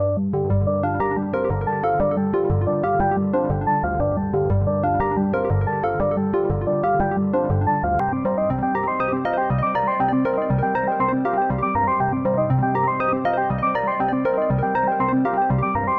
描述：谢尔比，2岁的金毛猎犬，为了玩具不断吠叫。 录制在室内，有地毯的房间，有立体声。
Tag: 树皮 吠叫 金色 金色猎犬 猎犬